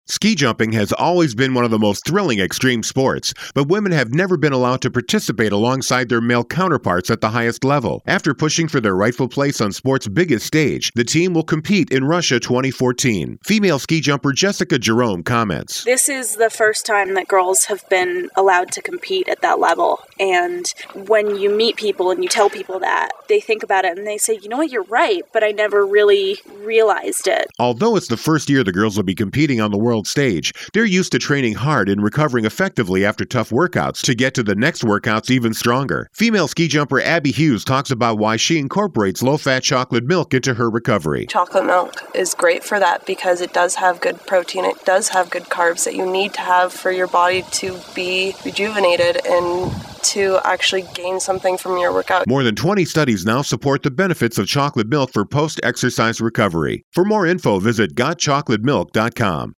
December 16, 2013Posted in: Audio News Release